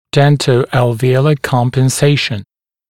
[ˌdentə(u)əlvɪ’əulə ˌkɔmpən’seɪʃ(ə)n][ˌдэнто(у)элви’оулэ ˌкомпэн’сэйш(э)н]дентоальвеолярная компенсация, зубоальвеолярная компенсация